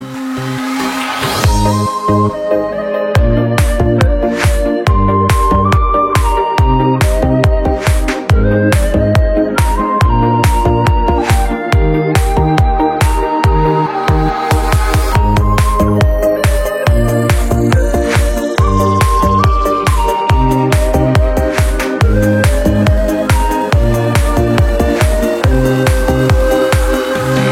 Nhạc Chuông Remix